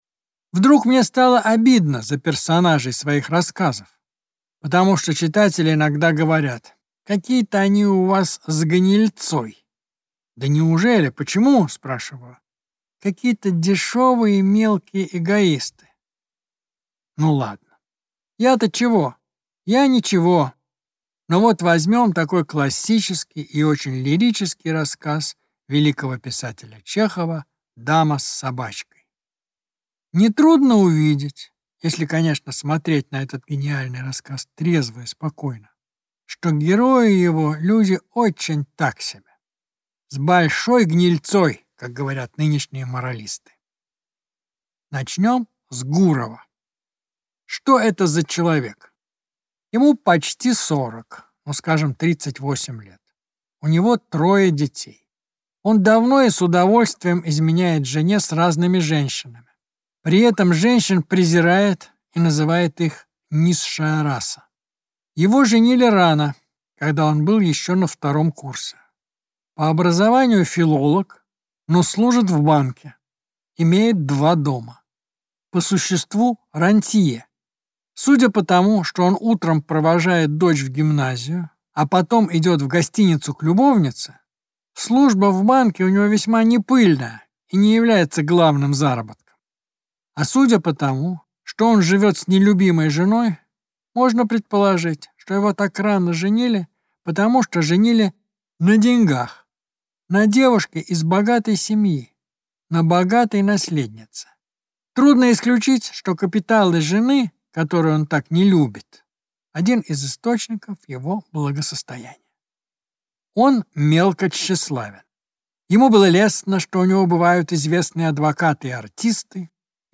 Аудиокнига Третье лицо | Библиотека аудиокниг